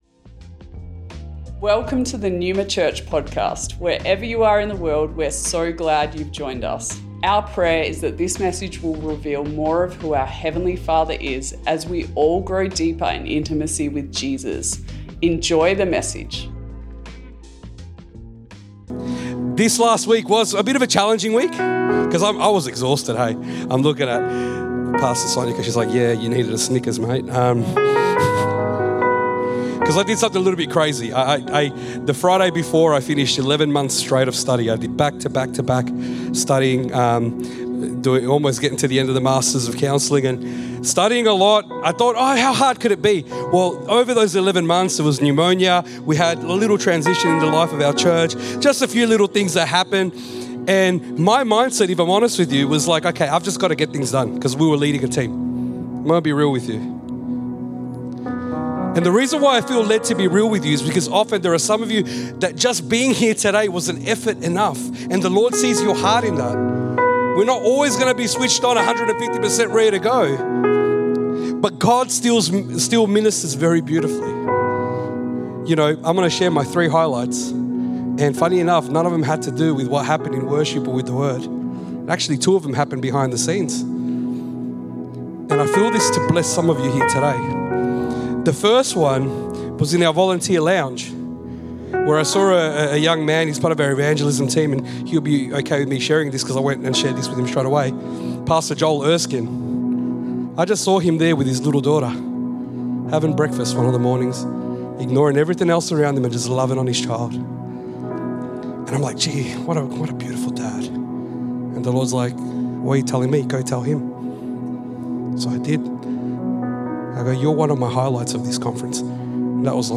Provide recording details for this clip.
Originally recorded at Neuma Melbourne West July 7th 2024